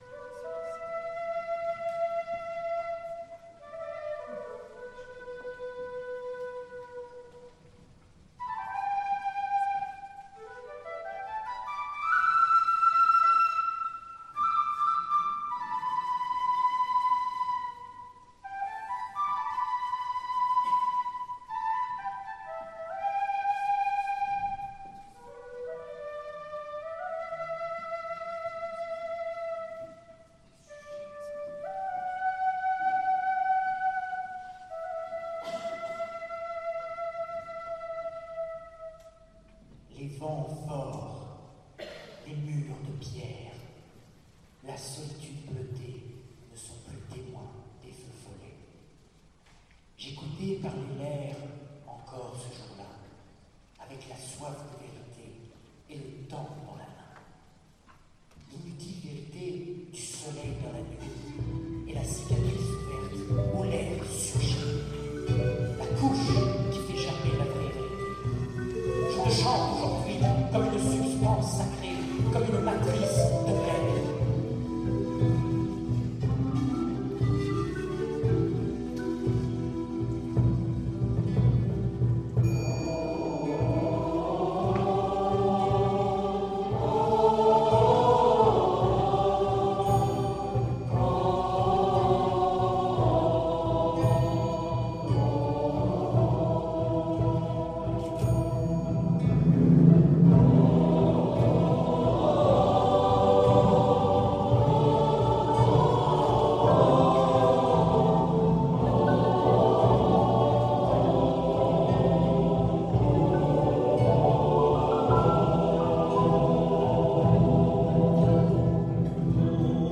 en l’église Saint Jean Bosco
Le Concert